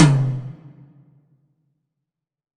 WTOM 3.wav